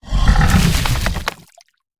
Sfx_creature_snowstalker_dry_fur_01.ogg